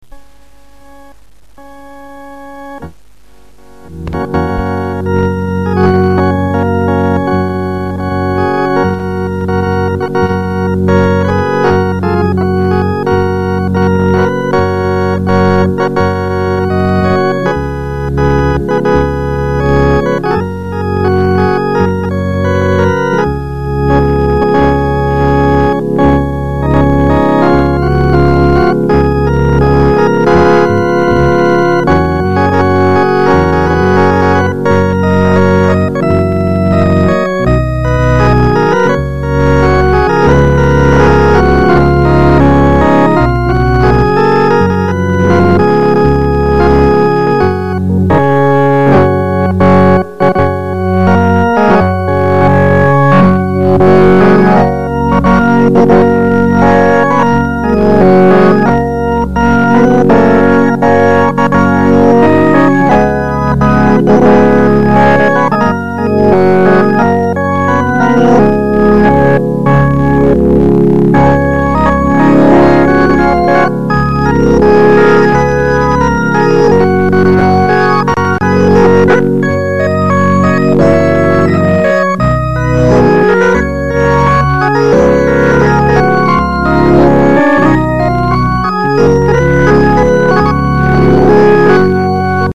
Organ.